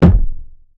Bongo15.wav